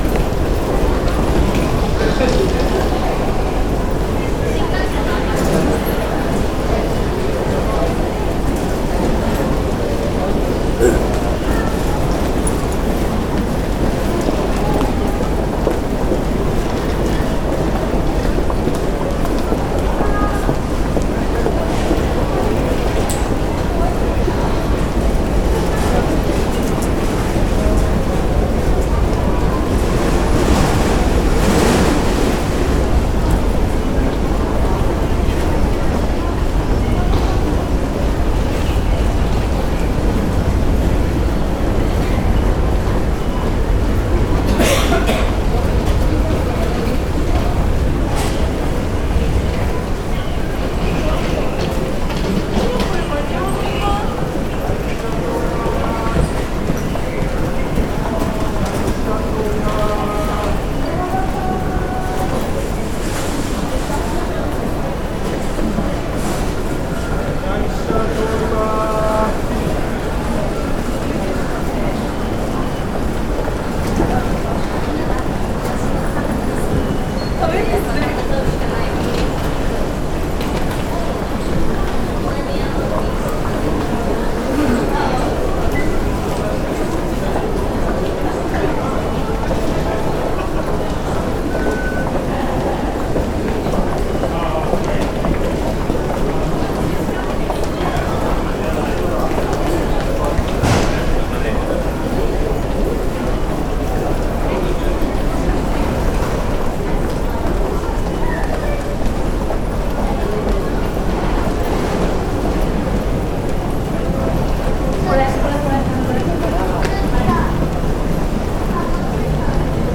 東京の音
東京駅通勤４/終わりかけ
Tokyo_St4_morn.mp3